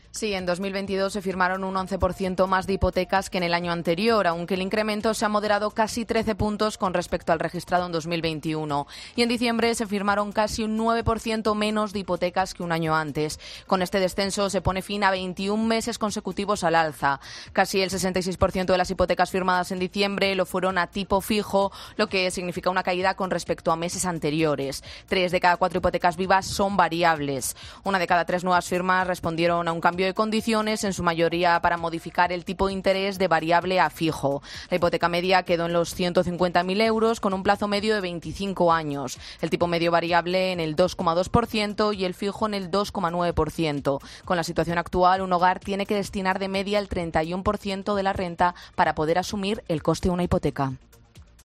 Escucha la crónica de redactora de COPE